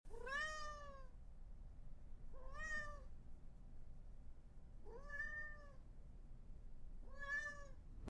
Kočka ráda spinká na vyhřátém místě, ale když chce pozornost, slyšíte: „Mňau!“